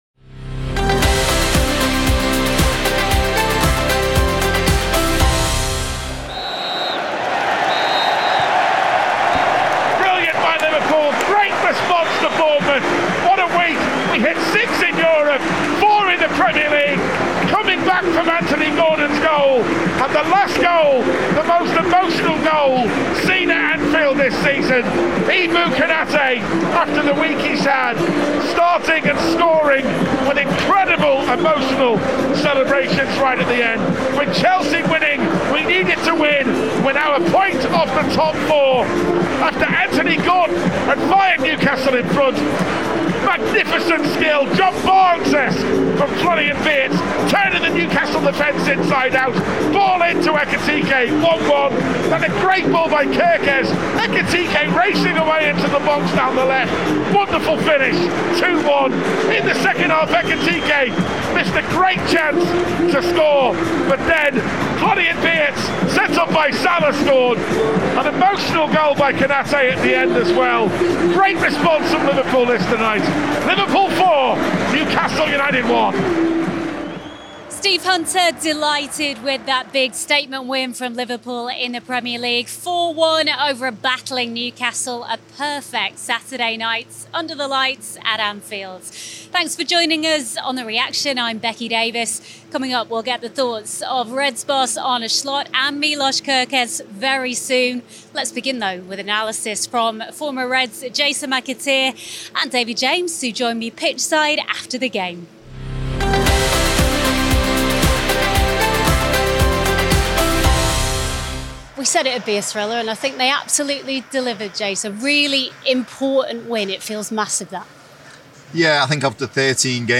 Former Reds Jason McAteer and David James also provide analysis from pitchside after the game.